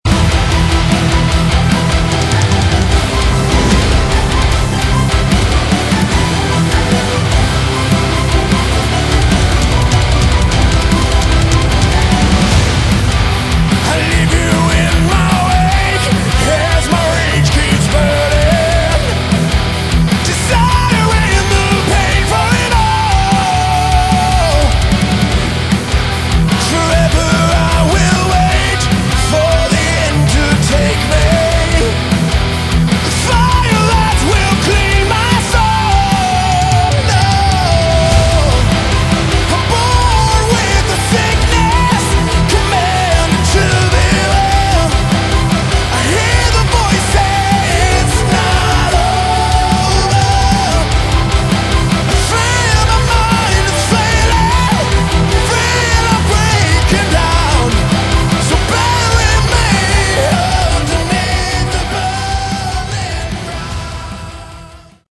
Category: Melodic Metal
vocals, guitars
bass
drums